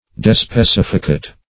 Despecificate \De`spe*cif"i*cate\, v. t. [Pref. de- (intens.) +